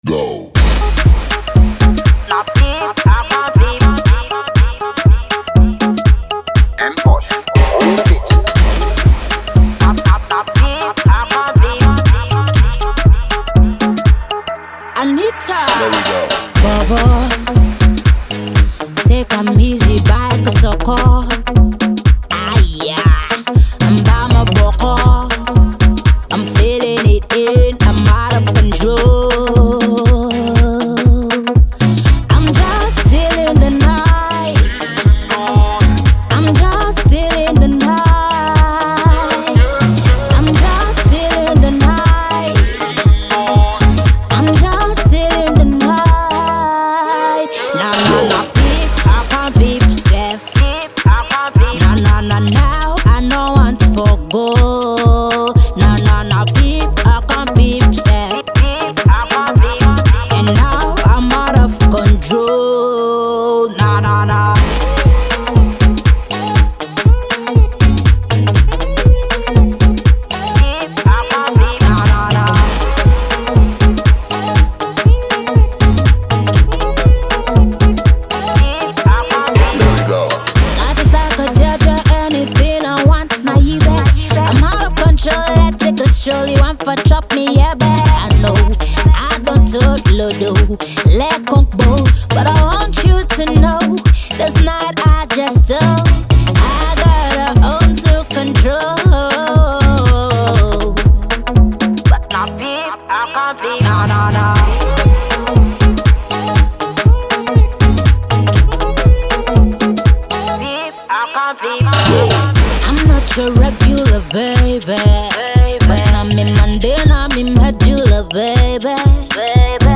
one of Sierra Leone's top female vocalists